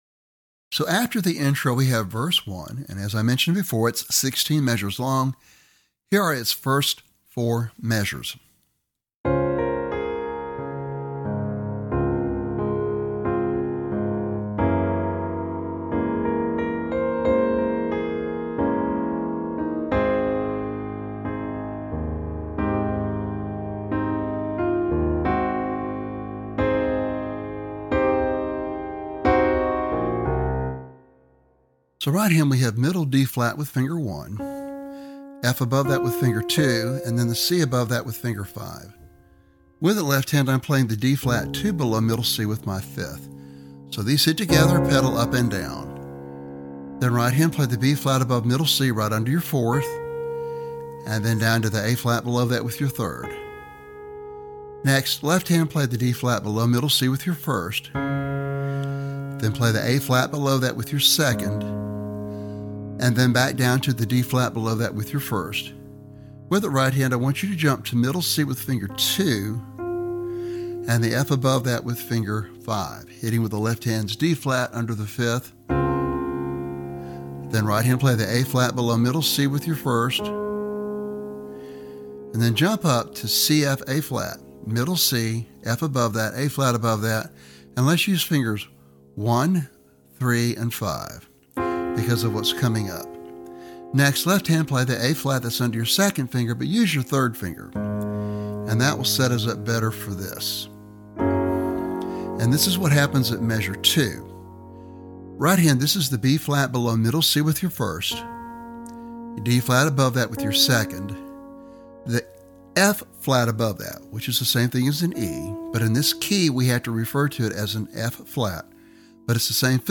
piano solo
This song is rated at an intermediate level.